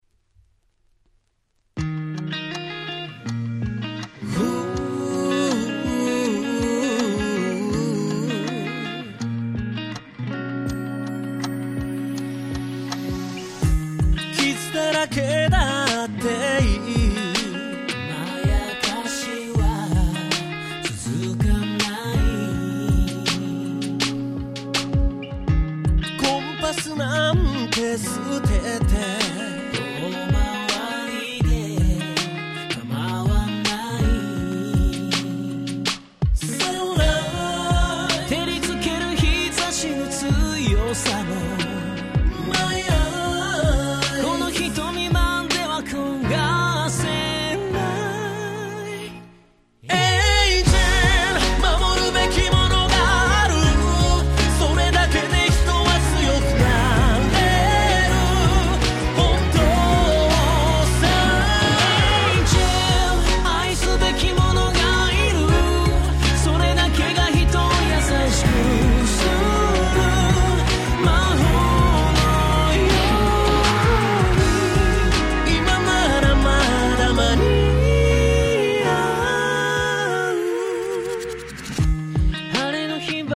19' Nice Japanese R&B !!